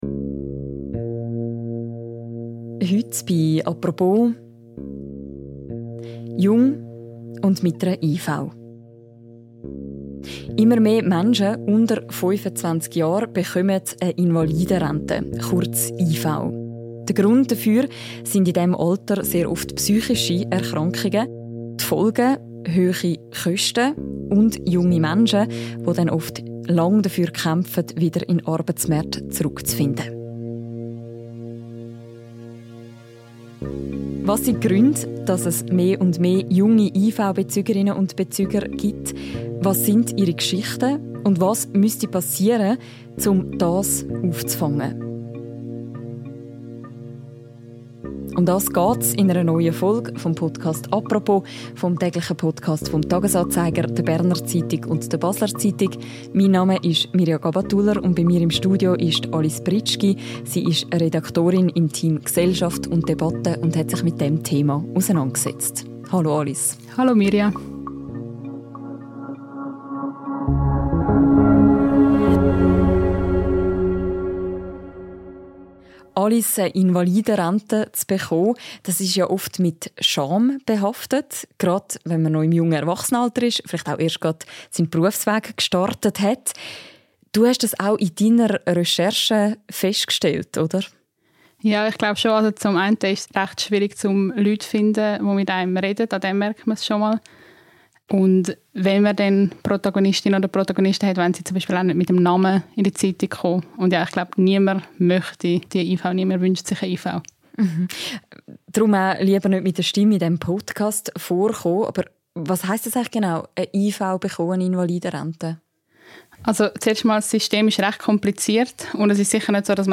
In einer neuen Folge des täglichen Podcasts «Apropos» erklärt sie die verschiedenen Ansätze – und lässt einen Psychiater und einen Psychologen selbst zu Wort kommen.